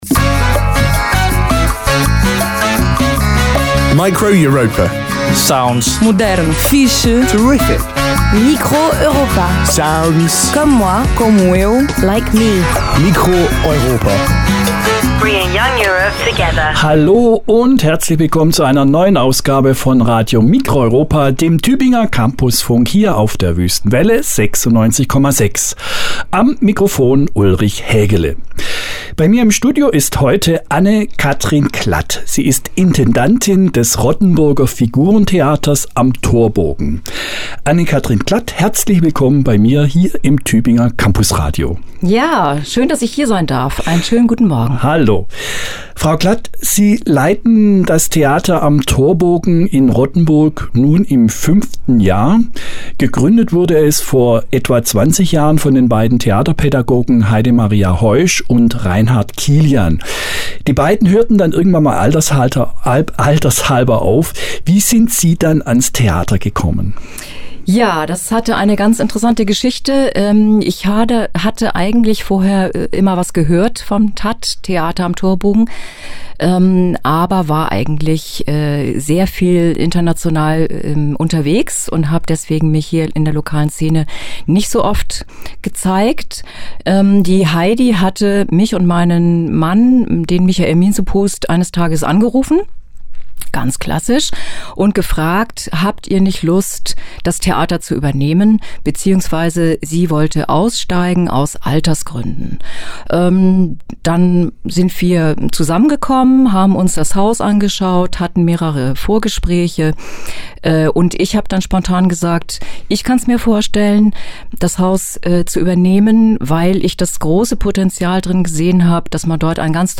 Theater am Torborgen Rottenburg: Studiogespräch
Form: Live-Aufzeichnung, geschnitten